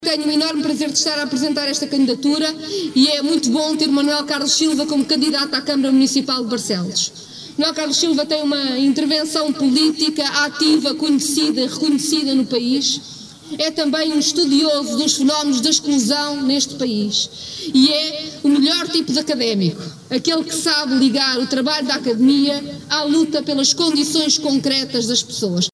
O Bloco de Esquerda de Barcelos apresentou, ao final da manhã desta segunda-feira no Largo da Porta Nova, a candidatura às autárquicas deste ano.